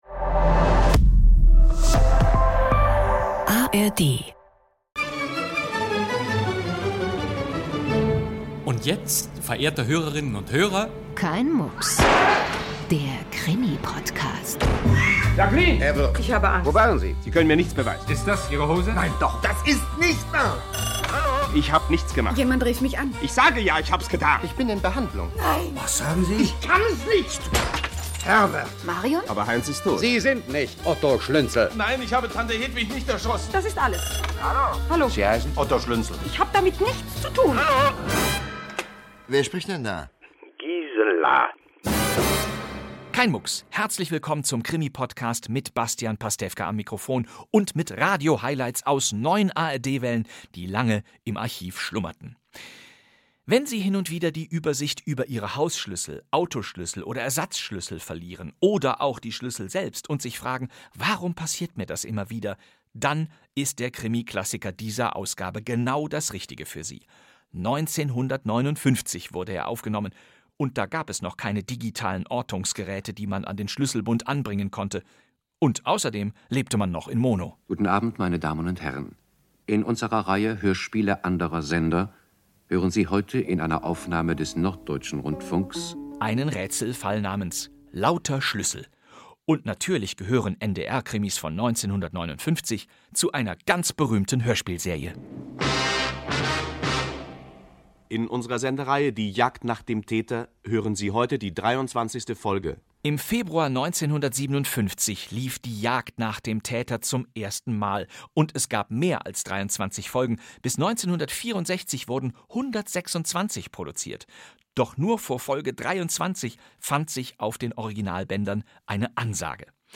Das sind die Zutaten für die neue Kein-Mucks-Staffel – Bastian Pastewka holt Kriminalhörspiel-Klassiker aus den Archiven: aus der Zeit, als die Magnetbänder rauschten und das Mikrofon keine Gnade mit kleinen Patzern und raschelnden Skript-Seiten hatte.
Kein Mucks ist ein Hörspiel-Podcast mit Krimi-Klassikern aus allen Rundfunkarchiven der ARD, moderiert von Bastian Pastewka. Jede Woche ein Fundstück aus den Archiven, dazu Bonusinformationen, Biografisches und Nonsens.